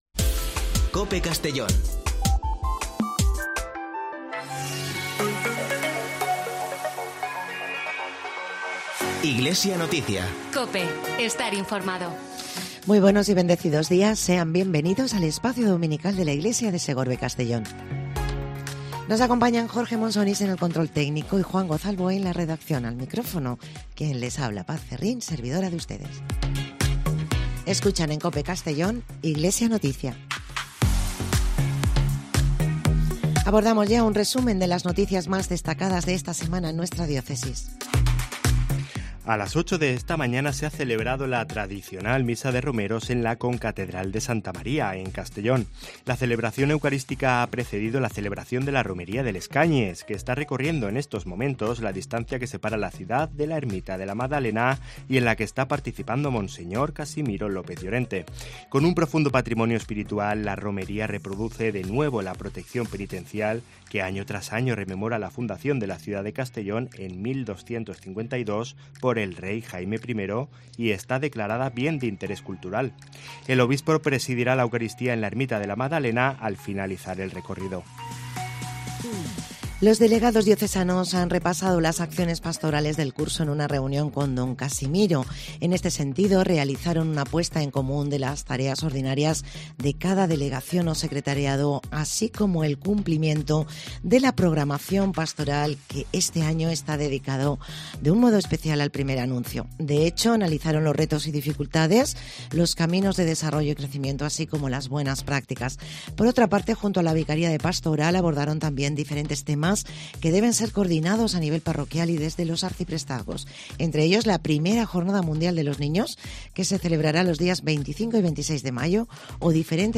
Espacio informativo de la Diócesis de Segorbe-Castellón